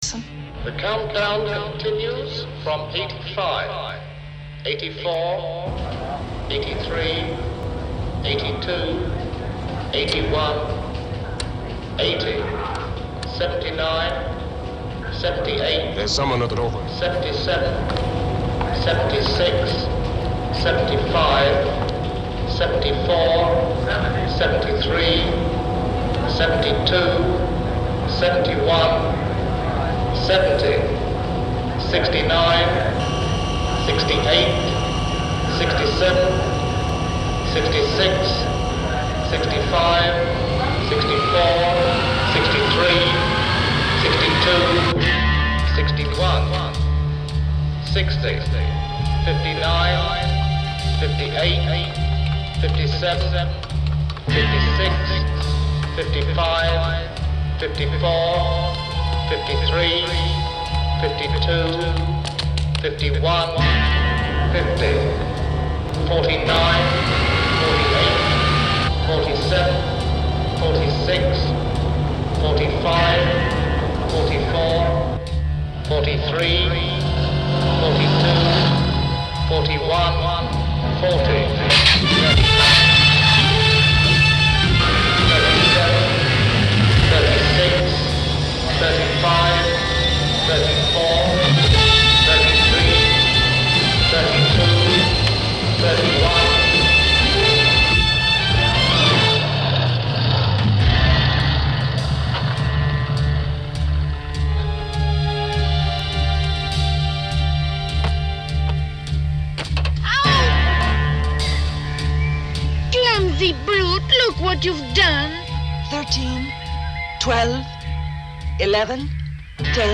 As you may have guessed, the audio to which I've linked is actually from a 1969 movie in which something does blow up.
countdown.mp3